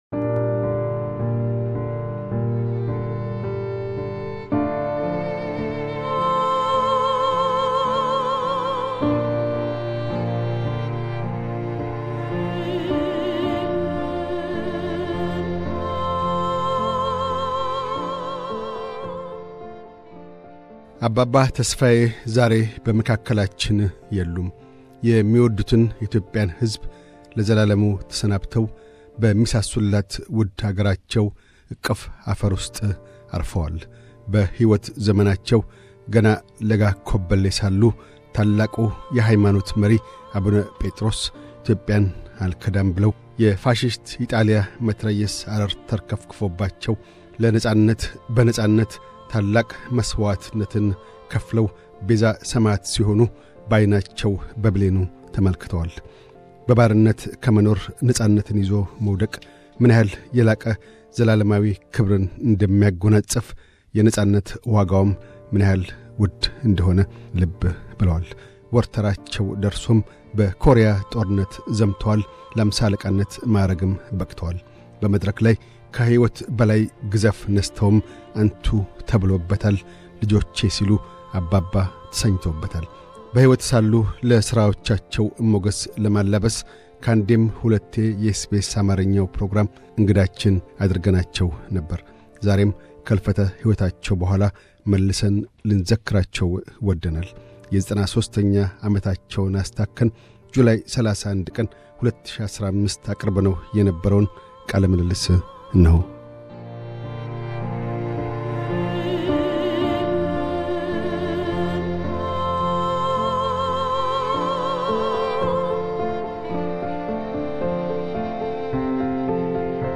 አባባ ተስፋዬ ሳህሉ፤ በወርሃ ኦገስት መጀመሪያ ከዚህ ዓለም ለዘለዓለሙ ተለይተዋል። ታላቅ የመድረክ ሰውነታቸውን ለመዘከር ጁላይ 31, 2017 አስተላልፈነው የነበረውን ቃለ ምልልስ በከፊል እነሆን።